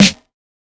Snare (7).wav